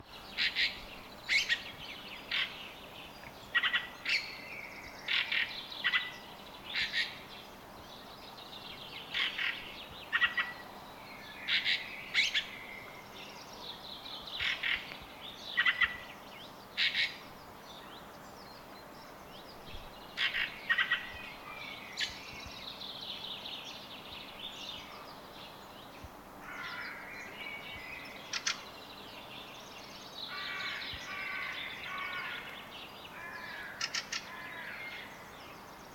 4. American Robin (Turdus migratorius)
Sound: Melodic and continuous chirping—one of the first bird songs of spring.